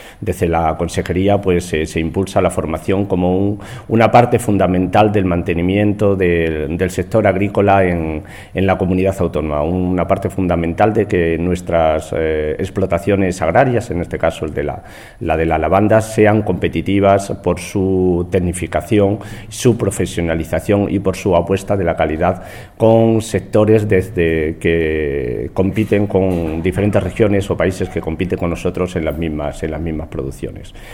El viceconsejero de Medio Ambiente, Agapito Portillo, habla de la importancia de la formación para mejorar la competitividad de las explotaciones agrarias.